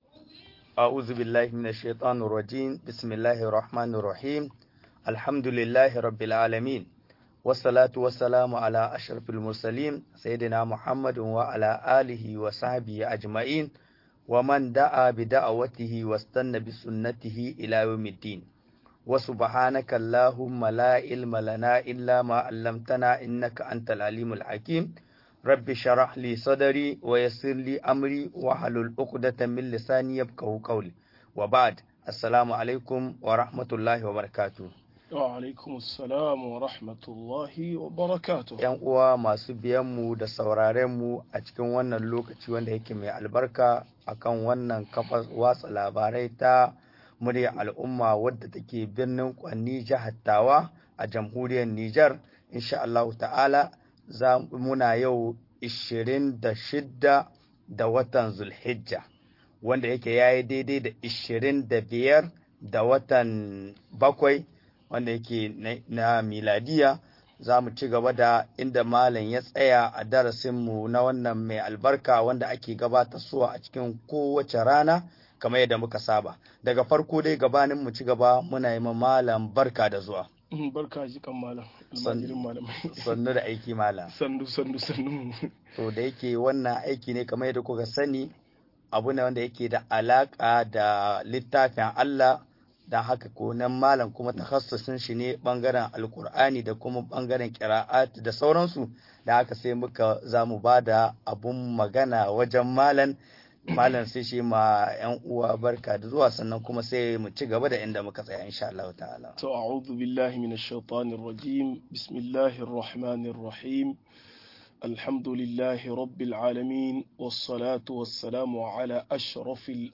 Sunayen Allah da siffofin sa-17 - MUHADARA